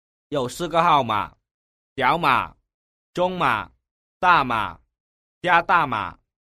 giẩu xư cưa hao mả : S,M,L,XL